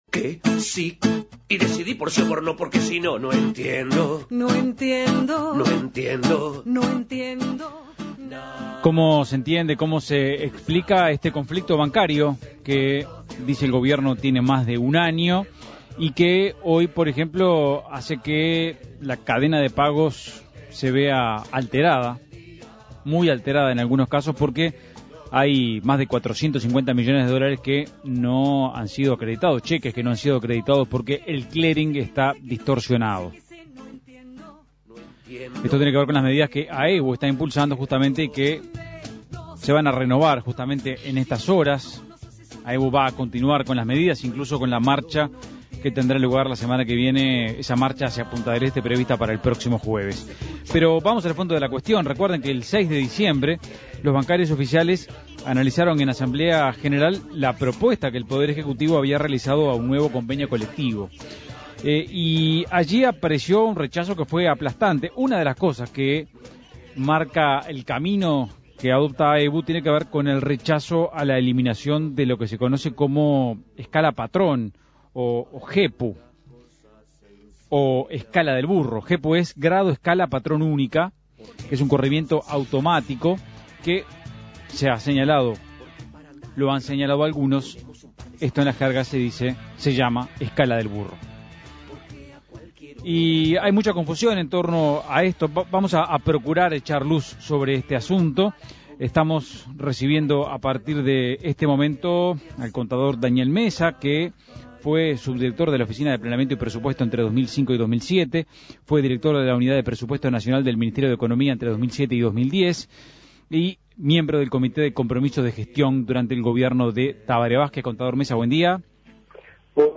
Entrevista al contador Daniel Mesa, exdirector de la OPP y de la Unidad de Presupuesto Nacional del MEF.